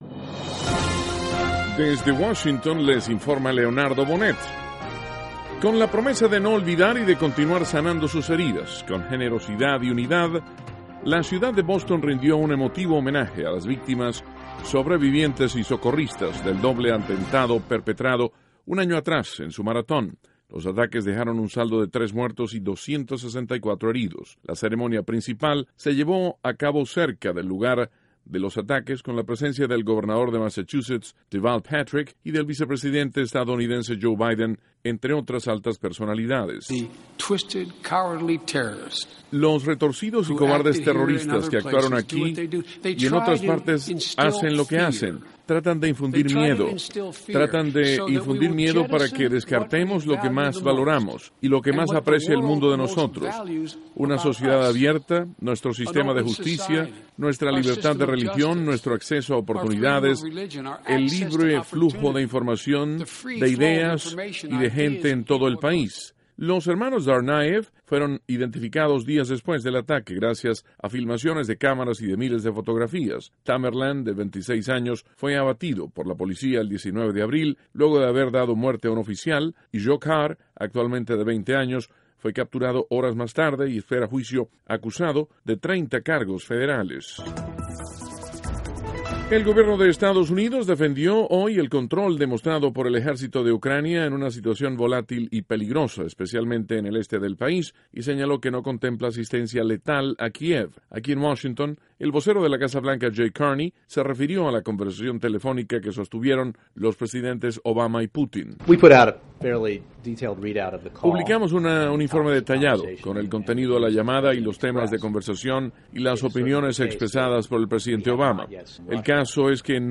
NOTICIAS - MARTES, 15 DE ABRIL, 2014
Duración: 3:30   Contenido:   1.- El vicepresidente Joe Biden asiste a ceremonia para conmemorar primer aniversario del atentado en la Maratón de Boston. (Sonido Biden)   2.- Jay Carney se refiere a la conversación telefónica entre los presidentes Obama y Putin. (Sonido Carney)   3.- Google adquiere la empresa Titan Aerospace